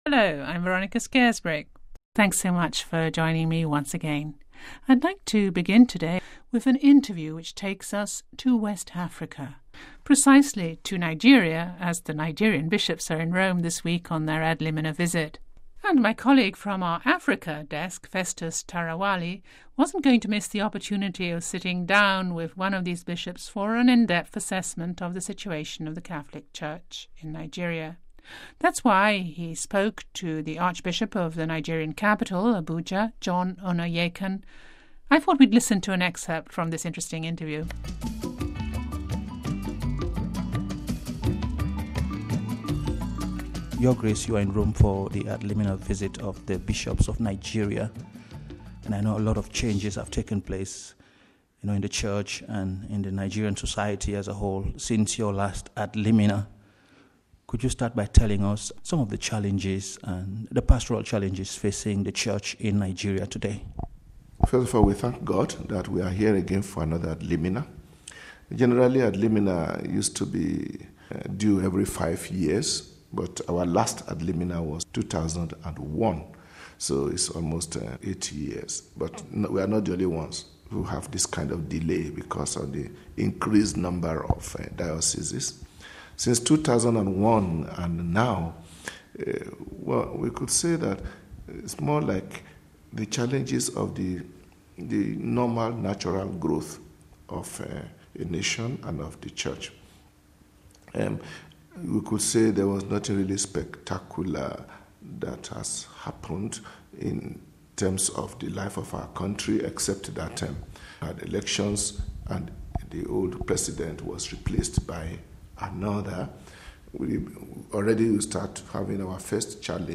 The Nigerian bishops are in Rome on their ad limina visit . Providing us with an opportunity to speak to the Archbishop of this West African's nation' s capital , Abuja , John Onaiyekan.